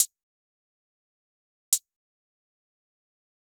Sicko Hi-Hat - Main .wav